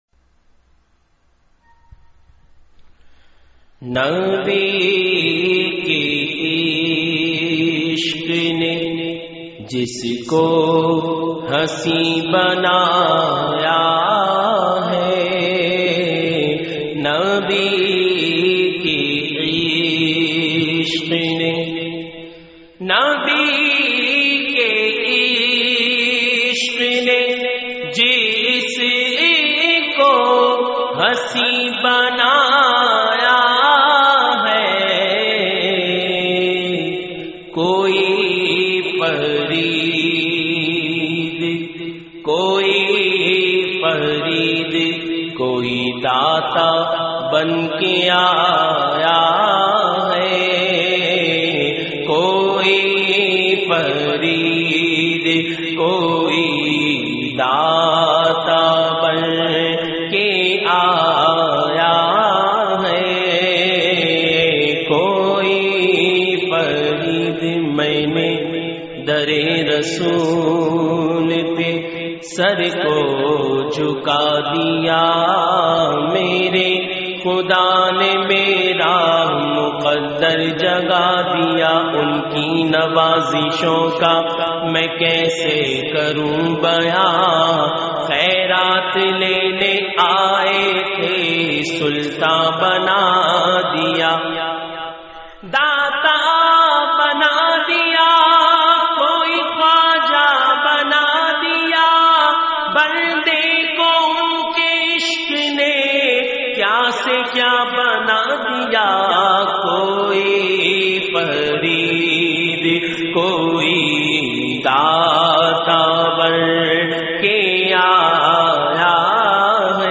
Category : Naat | Language : Urdu